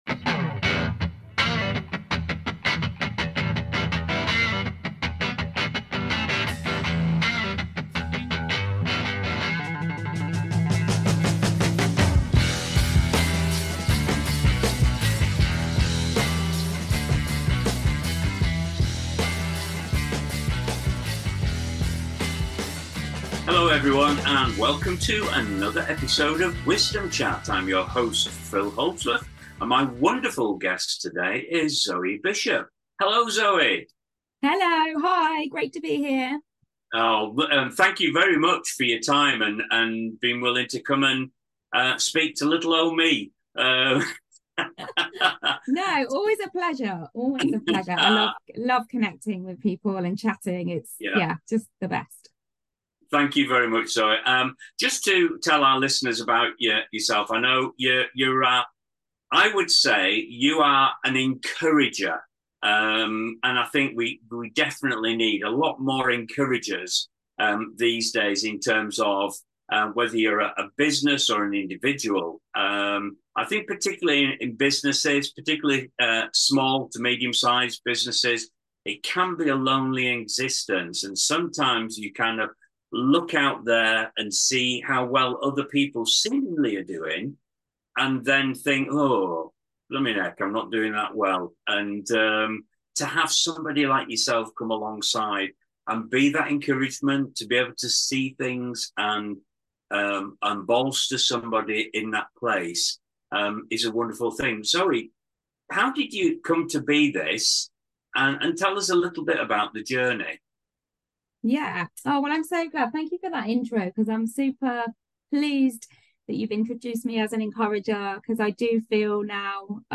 In a thought-provoking conversation